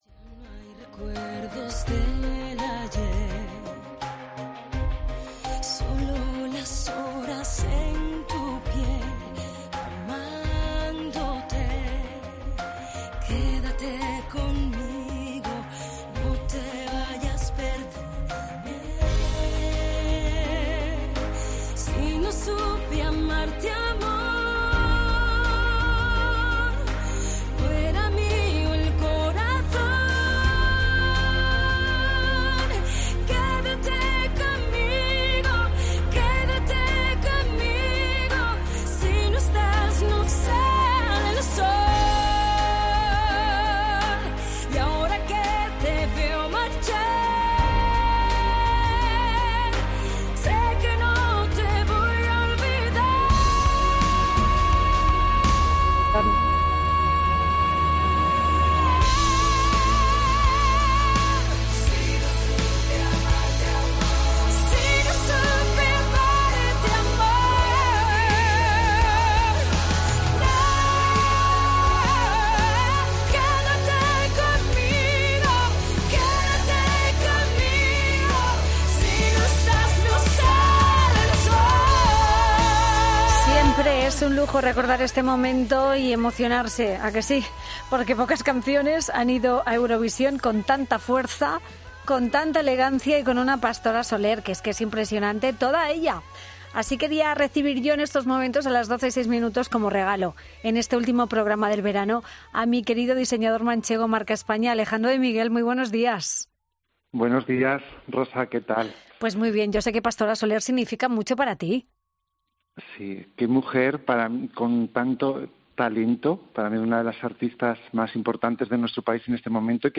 en tono desenfadado